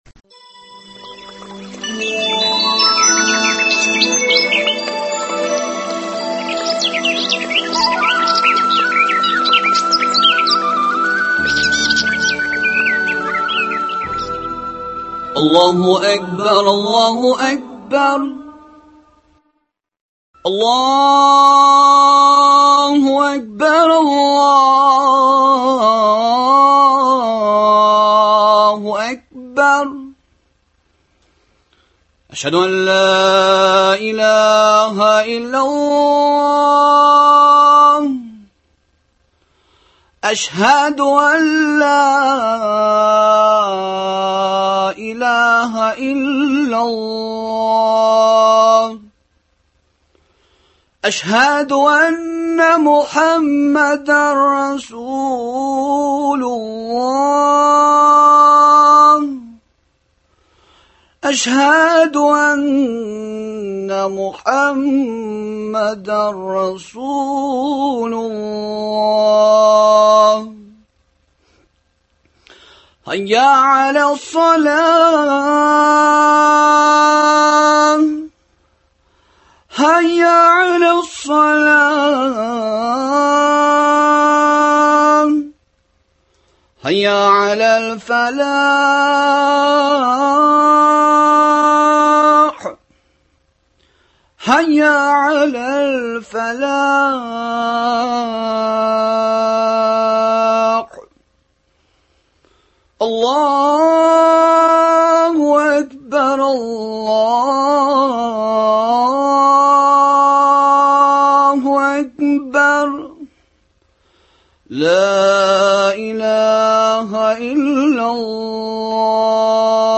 аңа ирешү юллары хакында әңгәмә.